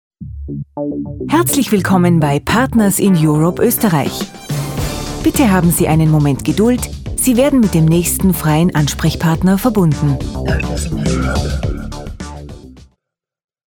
tirolerisch
Sprechprobe: Industrie (Muttersprache):
german female voice over artist (austria)